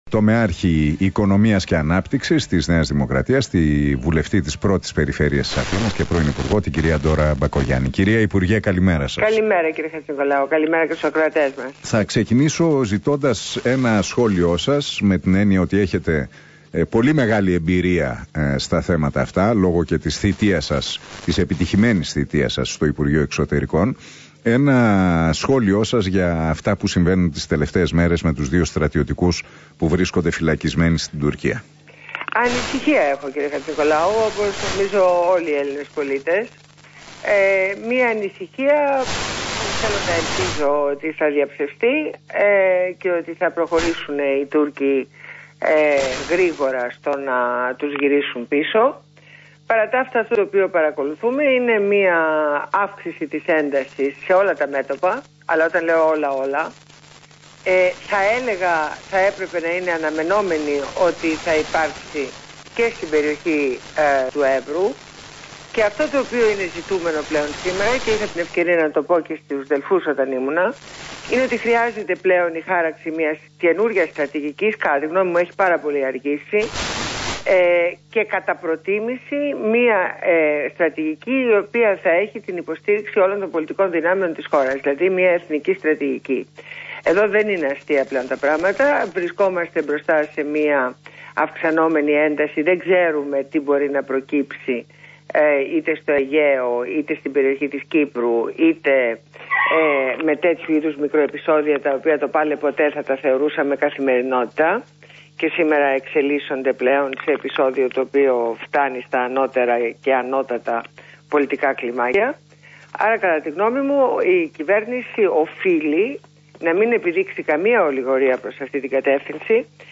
Συνέντευξη στο ραδιόφωνο Realfm